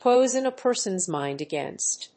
アクセントpóison a person's mínd agàinst…